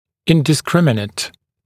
[ˌɪndɪ’skrɪmɪnət][ˌинди’скриминэт]неразборчивый, беспорядочный